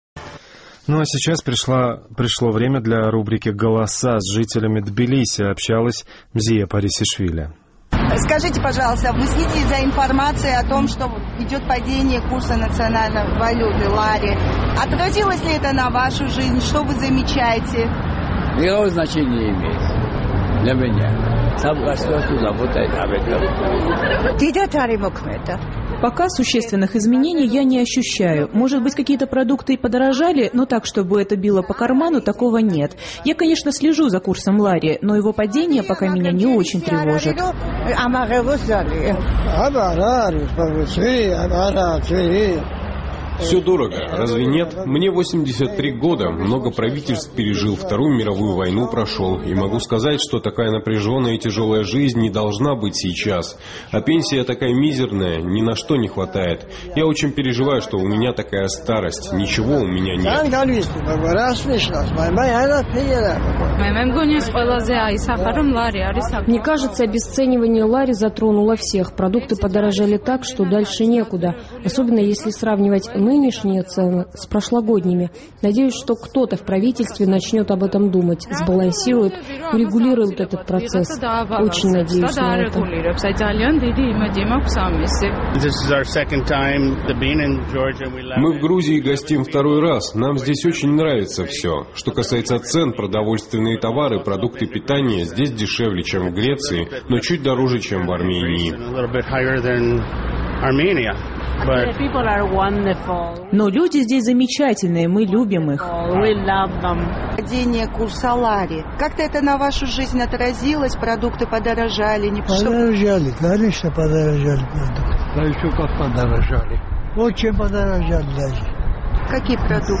В последнее время в Грузии наблюдается падение курса национальной валюты по отношению к доллару. Наш тбилисский корреспондент интересовался у жителей столицы, насколько этот процесс отразился на уровне их жизни?